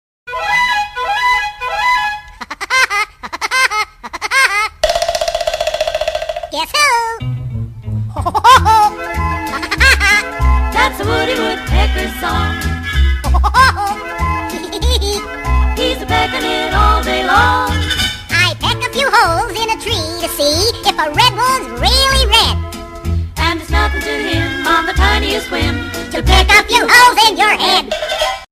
Kategorien: Tierstimmen